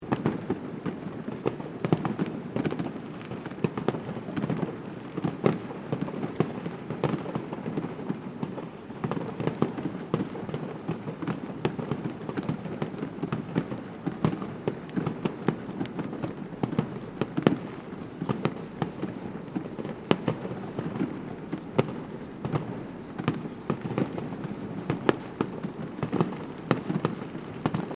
Fireworks
Fireworks-006.mp3